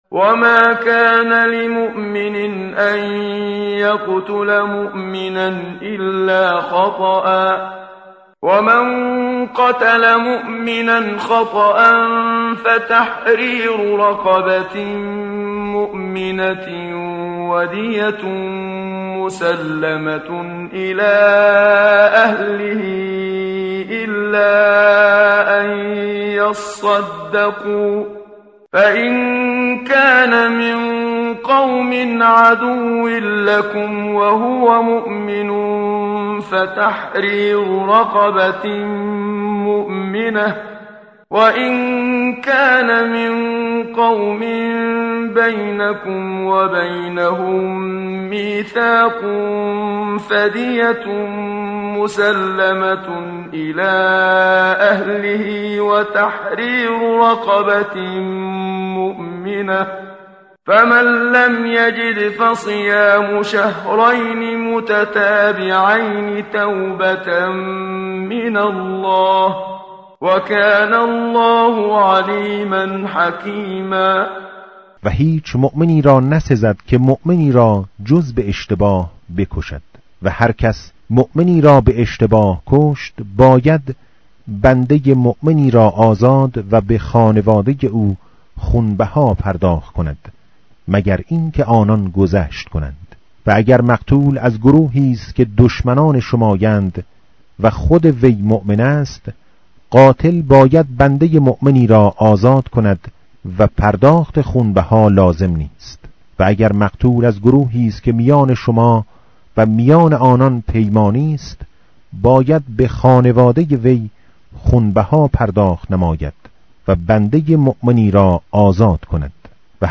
متن قرآن همراه باتلاوت قرآن و ترجمه
tartil_menshavi va tarjome_Page_093.mp3